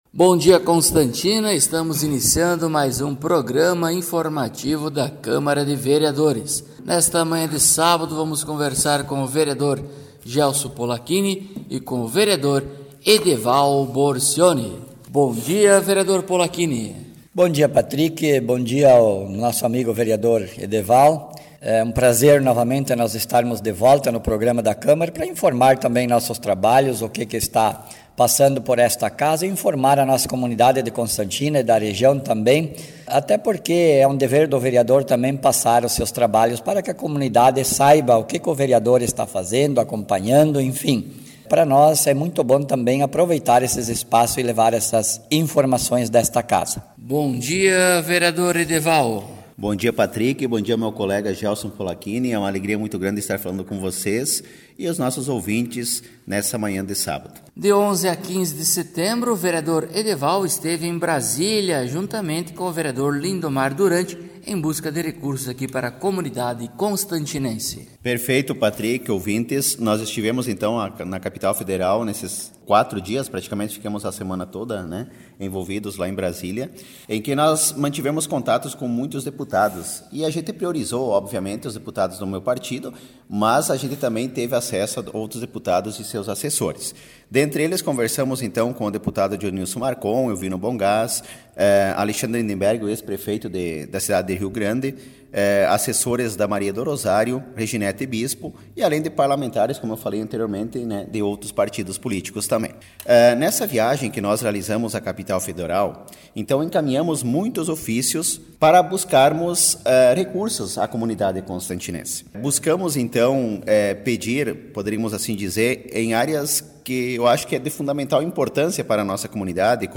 Acompanhe o programa informativo da câmara de vereadores de Constantina com o Vereador Edeval Borcioni e o Vereador Gelso Polaquini.